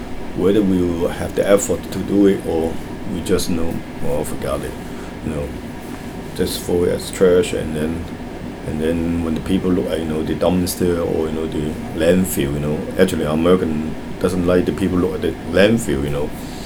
S1 = Taiwanese female S2 = Hong Kong male Context: S2 is talking about recycling in the United States.
S2 pronounces the initial voiceless TH sound in throw as [f] , and there is no clear [r] in the word.